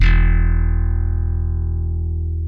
E1 5 F.BASS.wav